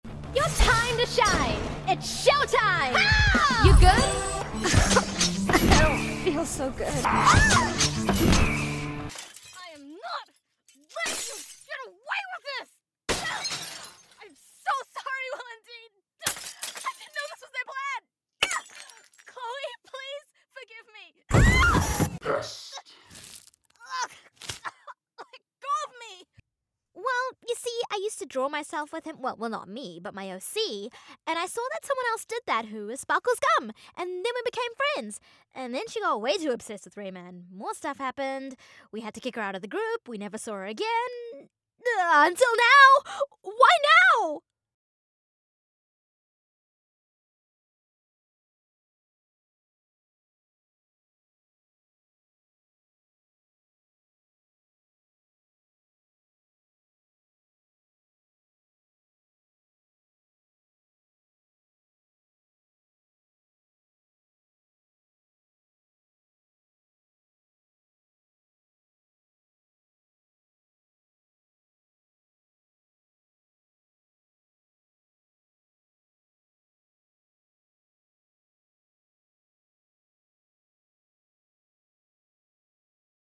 new zealand | character
NZAccent-Character.mp3